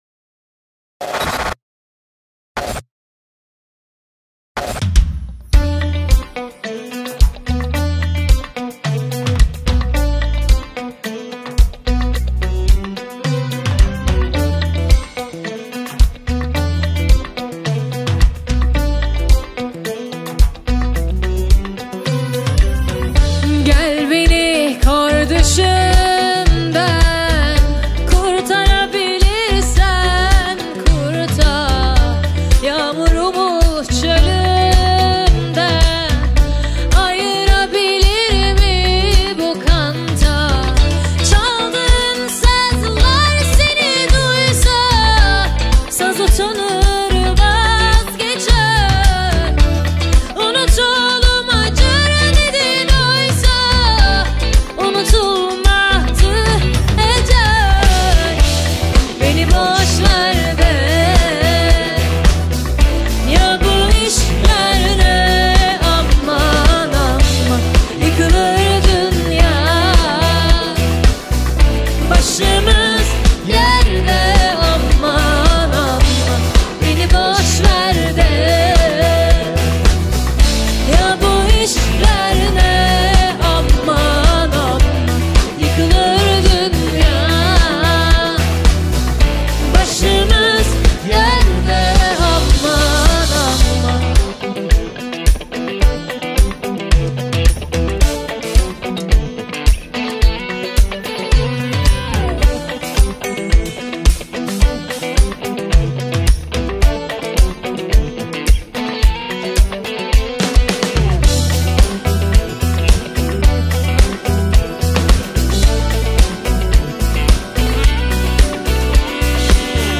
яркая поп-песня
выразительный вокал и харизматичная подача